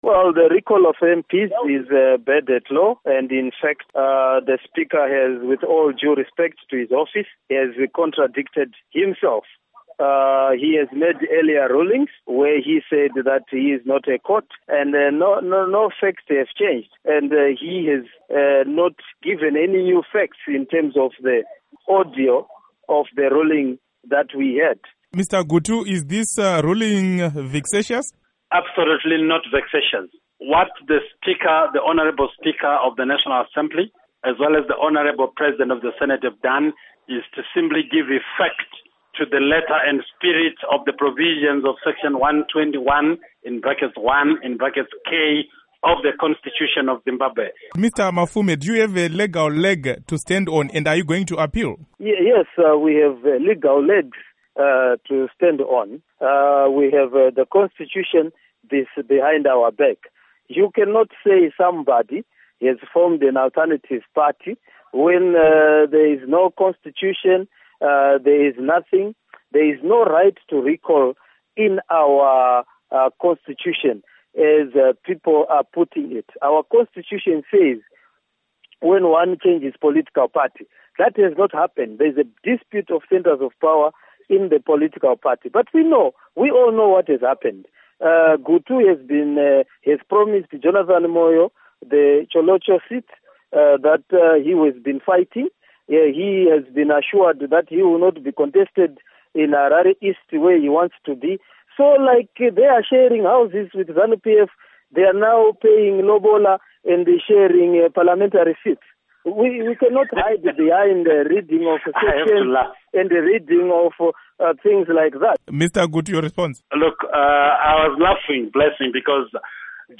Interview With Obert Gutu And Jacob Mafume